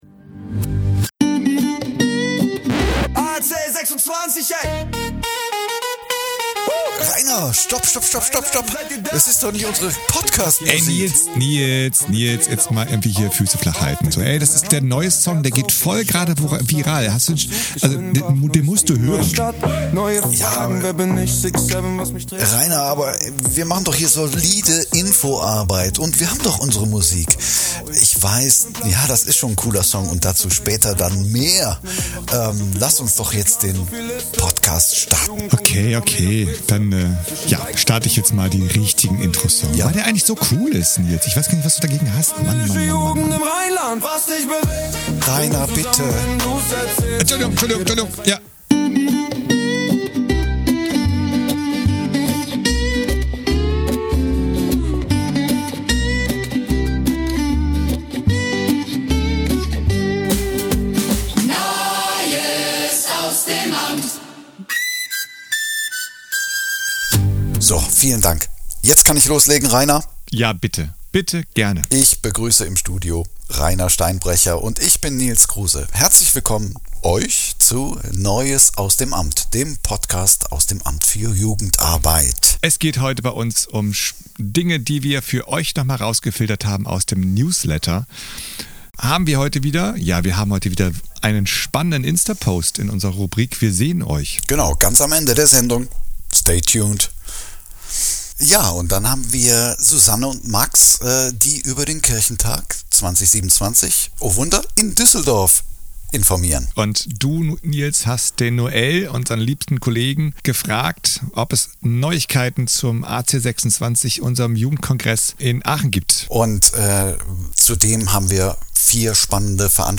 Interviews & Praxis-Einblicke